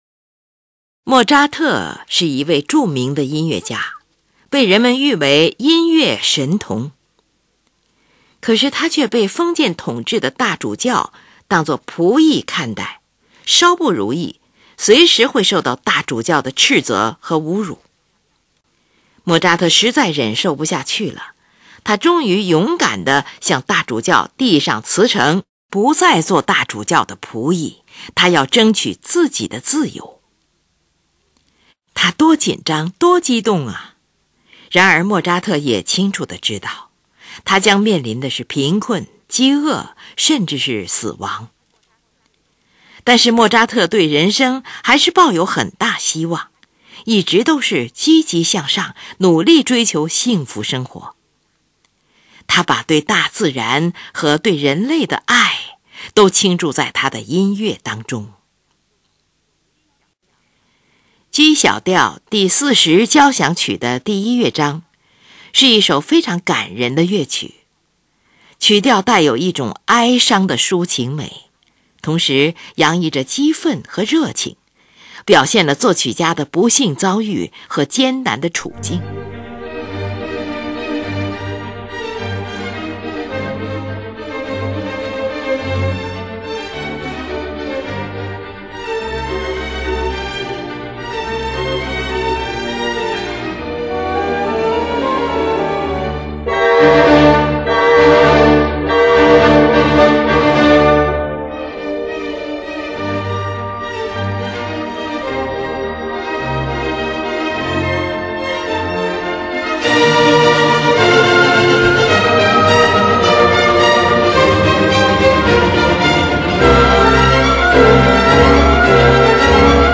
曲调带有一种哀伤的抒情美，同时洋溢着激奋和热情。
这部交响乐的乐队编配相当节省，它只有弦乐器组、木管乐器组和音响柔和的法国号而已，它不用小号，也没有定音鼓。